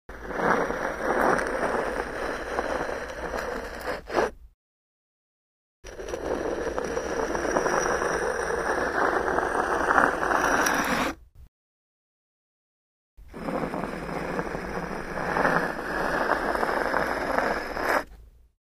Звуки камней
Камнем проводят по бетонному полу